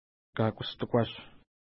Pronunciation: ka:kustukwa:ʃ
Pronunciation